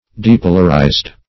Depolarize \De*po"lar*ize\, v. t. [imp. & p. p. Depolarized;